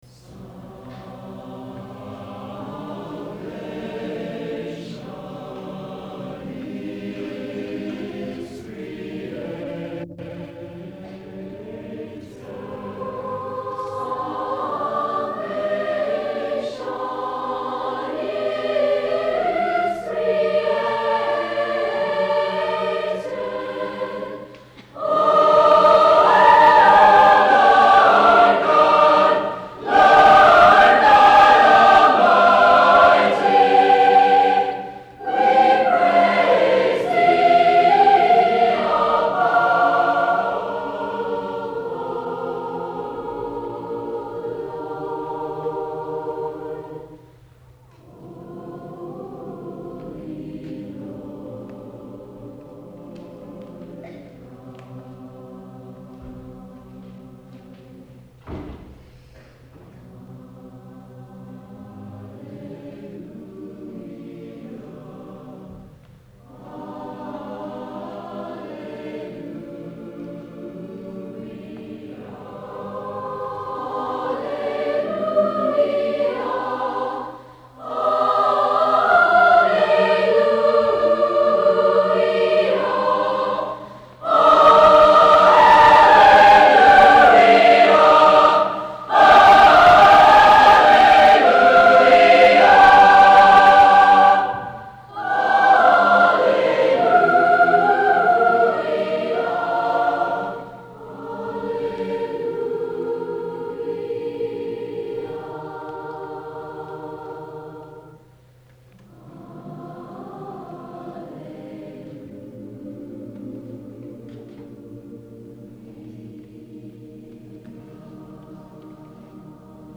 Spring Concert
Clay High Gym